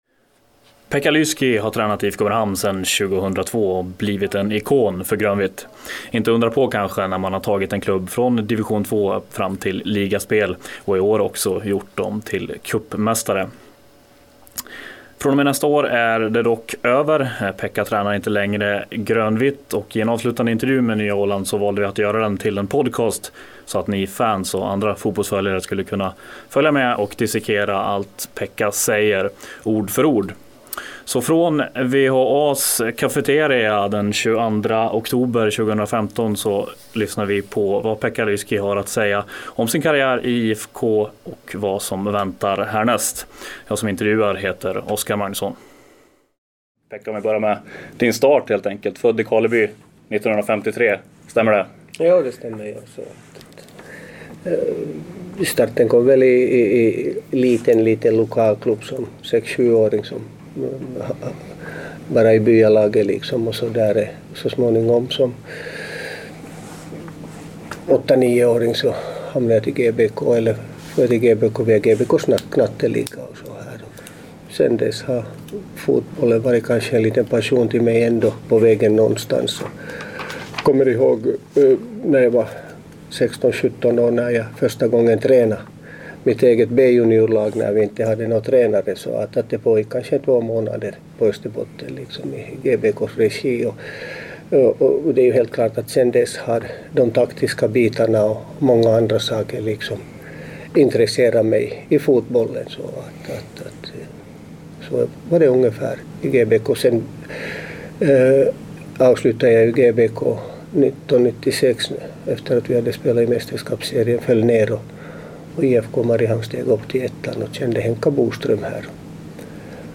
Det är svårt att hitta en lämpligare idrottsprofil för Nyan-sportens första podcast-intervju, och det nästan timslånga samtalet kan ni lyssna på i anslutning till den här artikeln.